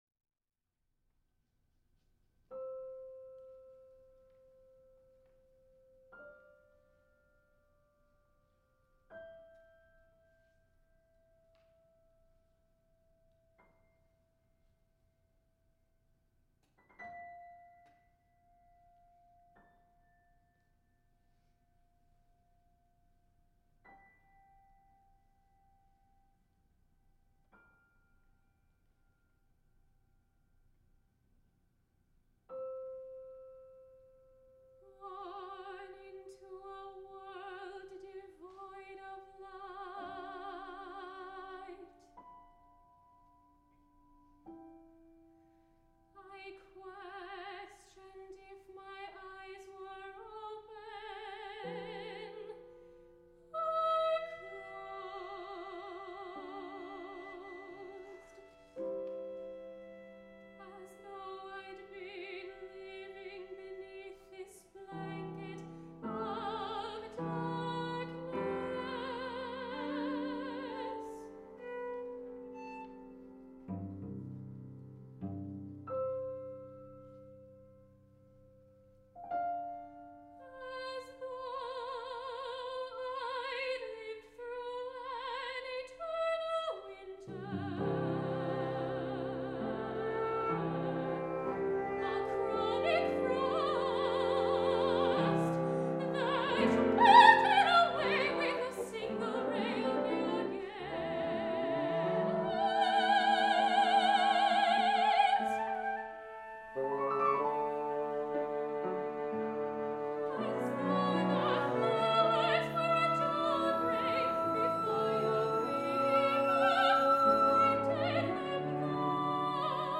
Chamber opera for soprano, baritone, sopranino recorder, bassoon, cello, and piano
Version for soprano, flute, bassoon, cello, piano
Recorded March 24, 2018 in the Conrad Choral Room at Bowling Green State University.